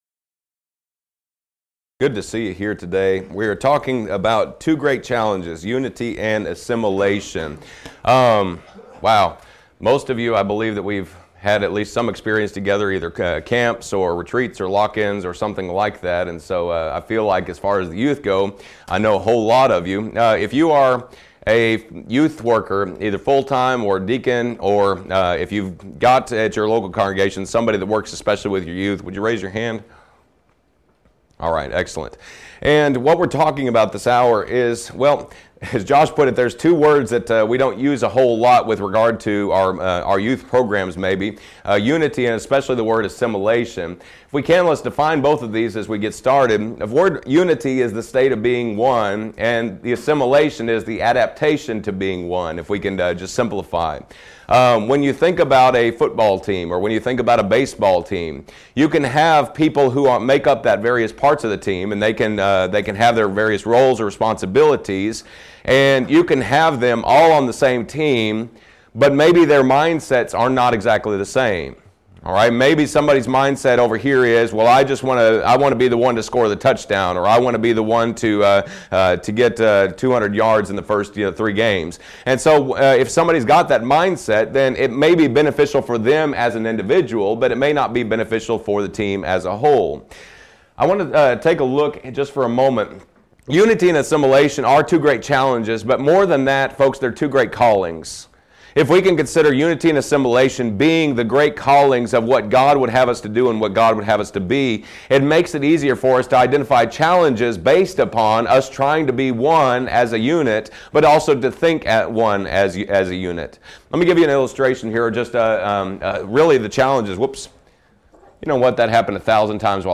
Event: 2016 Focal Point
lecture